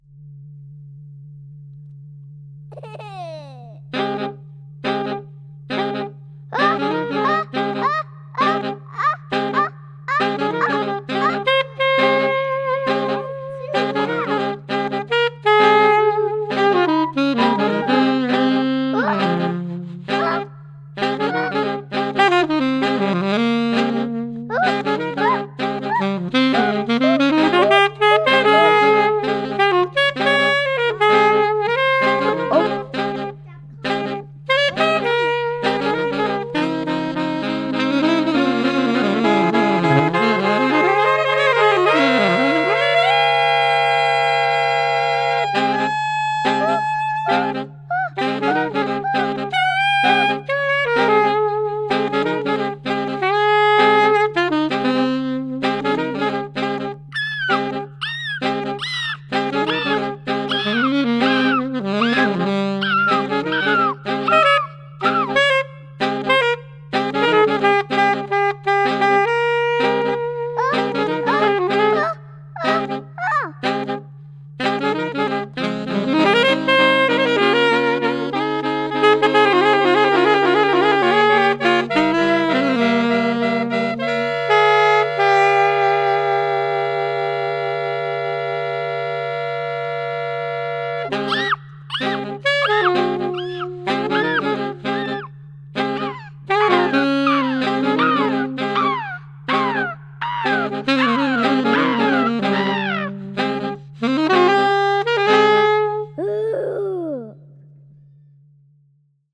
Recorded at home in Manhattan, and in Rockland County
alto saxophone
Stereo (Pro Tools)